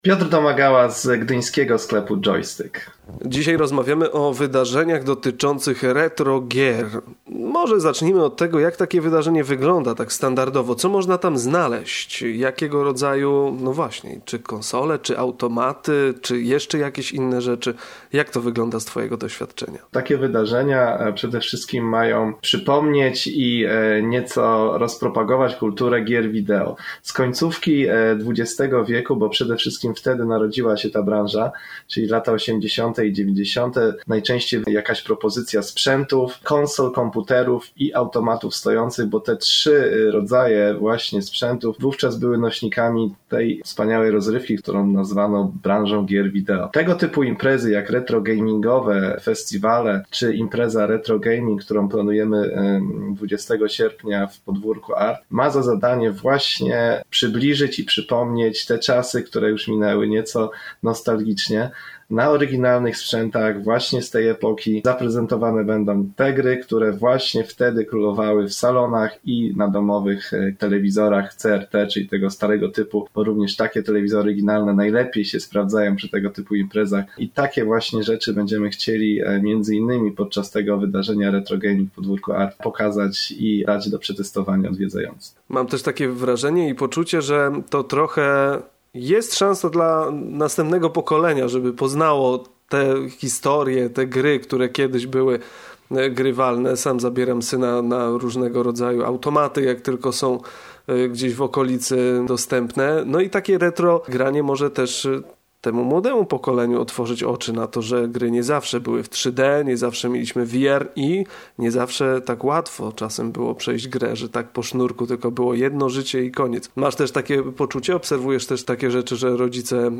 Dzisiaj o tym trendzie i dodatkowo zaproszenie. Rozmawiamy z naszym ekspertem od gier